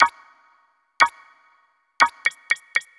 3_sec_countdown.ogg